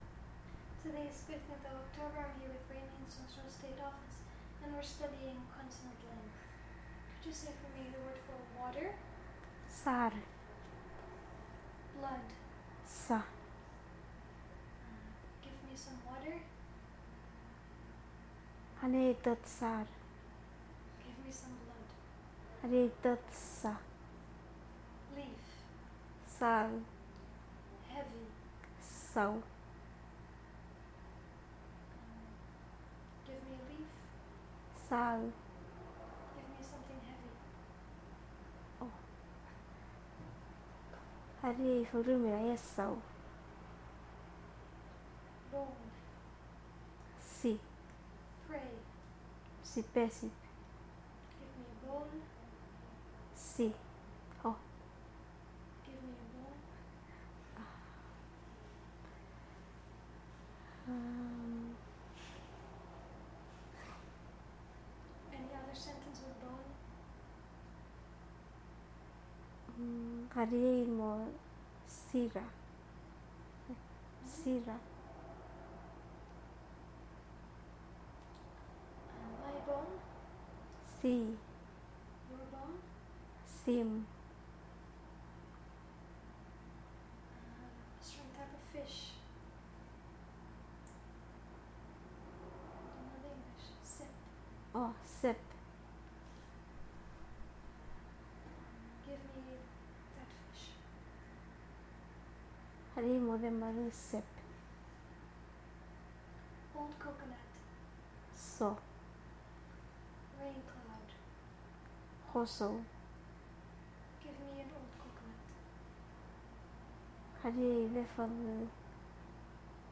digital wav file recorded at 44.1 kHz/16 bit on Zoom H2N
Echang, Koror, Palau